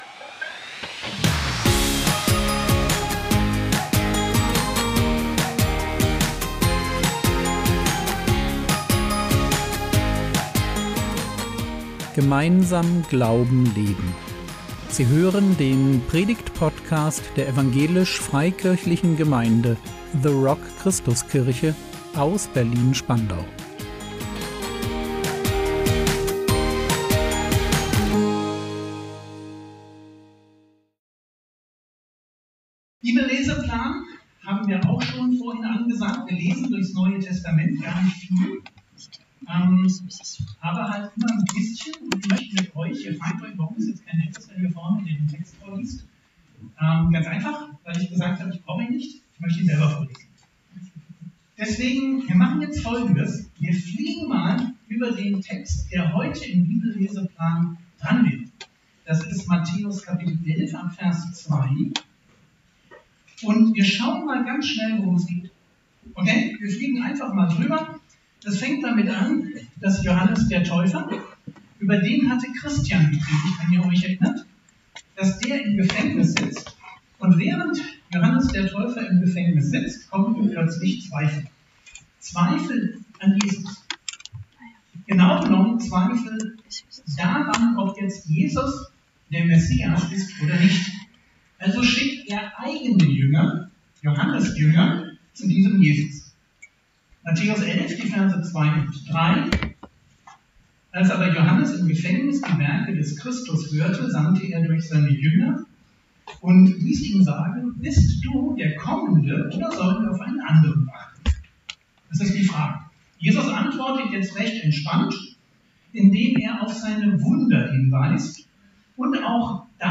Fragen stellen - Durchblick gewinnen | 18.01.2026 ~ Predigt Podcast der EFG The Rock Christuskirche Berlin Podcast